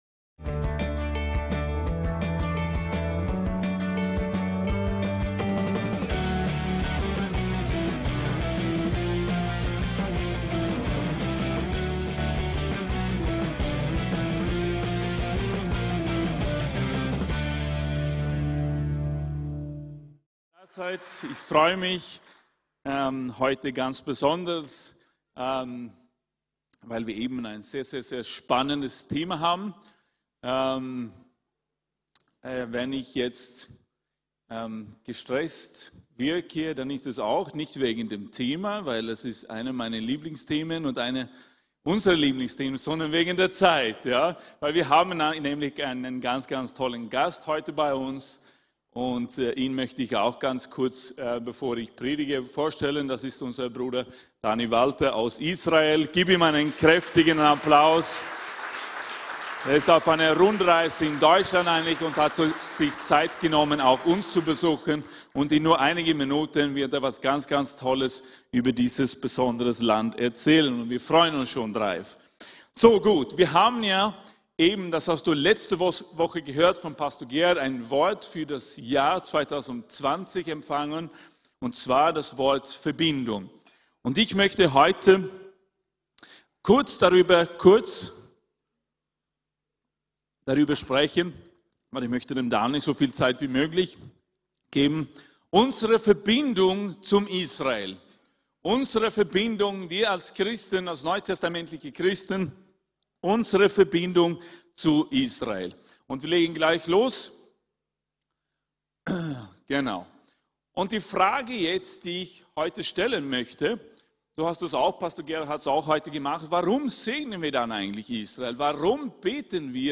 VERBINDUNG ZU ISRAEL ~ VCC JesusZentrum Gottesdienste (audio) Podcast